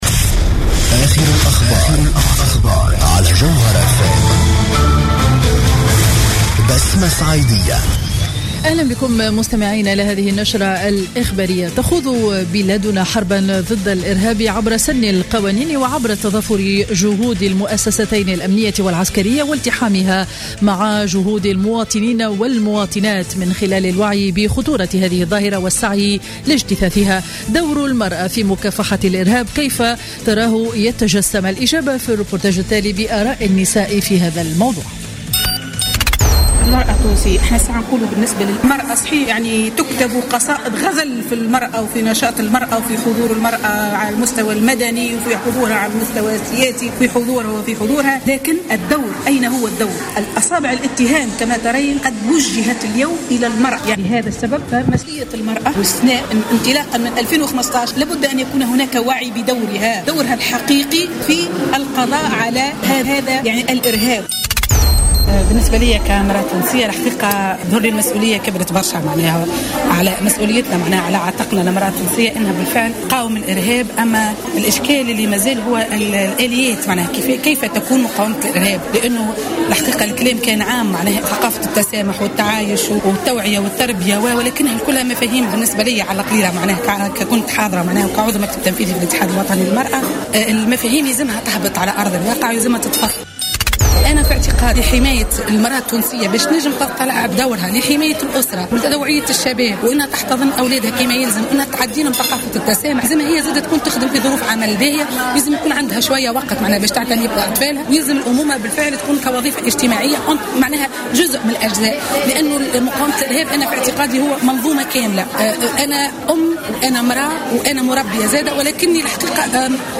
نشرة أخبار منتصف النهار ليوم الخميس 13 أوت 2015